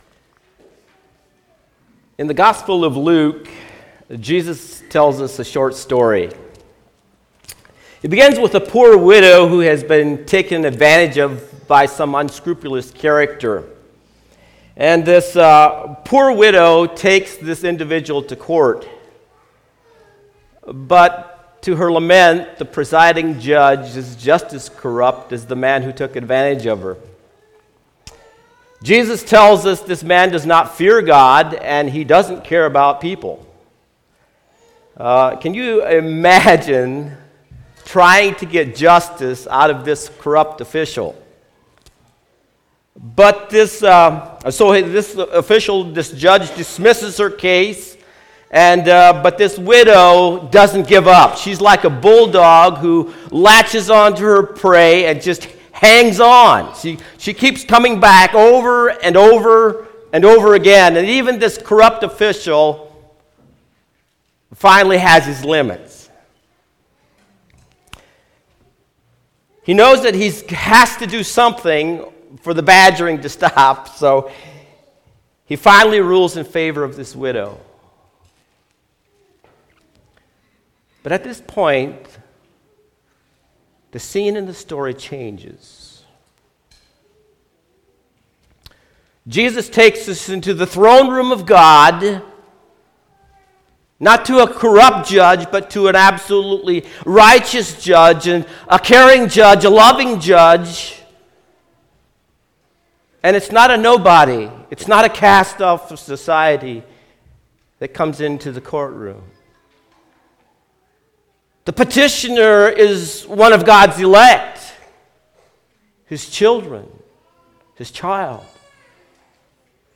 Sermons The Epistle of James